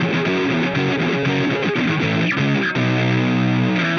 Power Pop Punk Guitar 03.wav